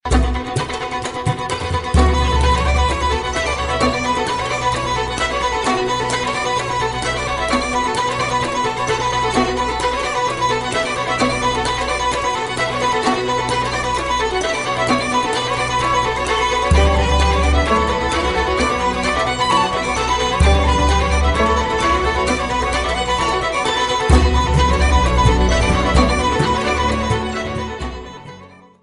Ирландский народный танец